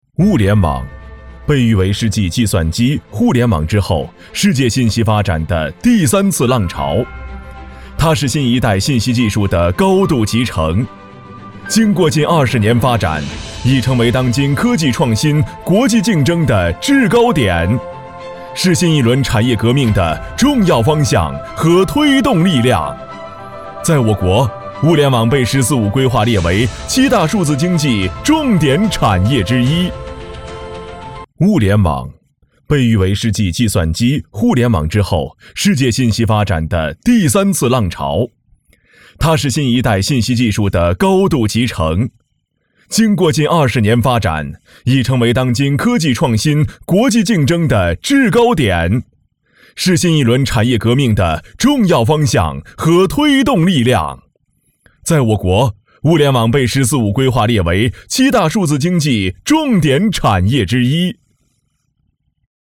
男24号配音师
专题片-男24-物联网，被誉为是继计算机.mp3